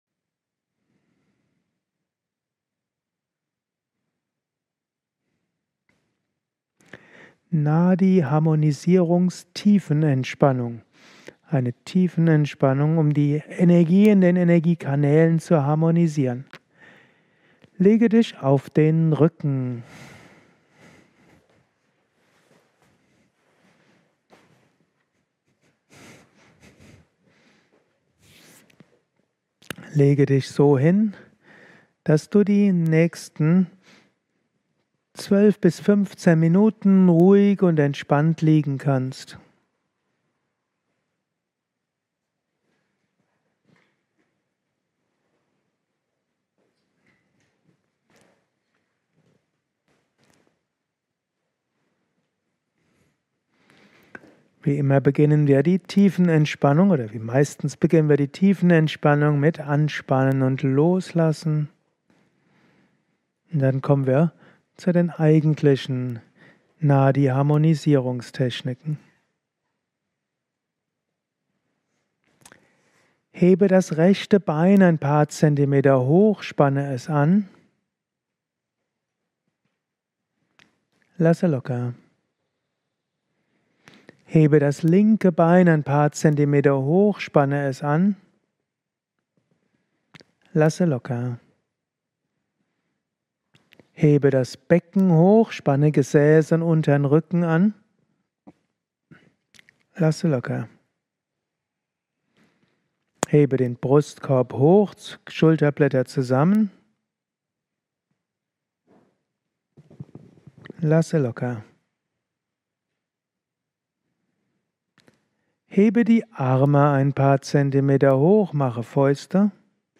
Harmonisiere deine Energien | Angeleitete Tiefenentspannung